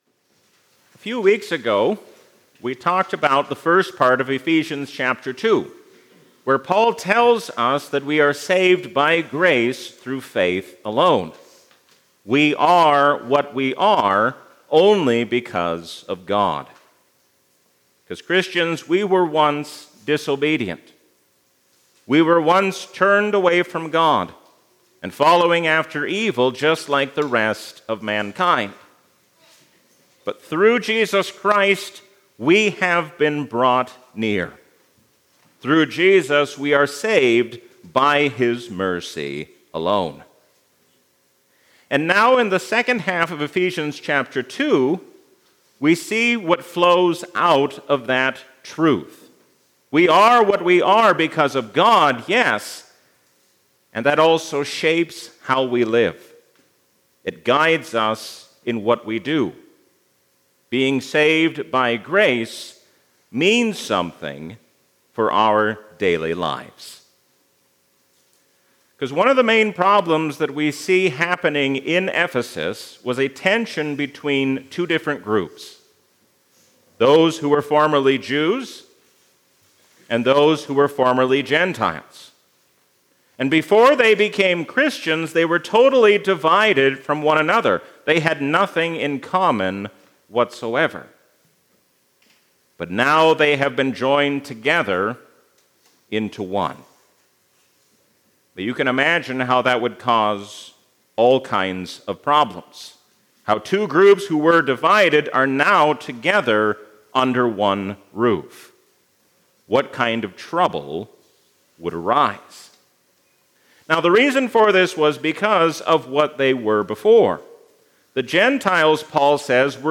A sermon from the season "Easter 2025." Why should we insist on our own way when we have been made one in Christ?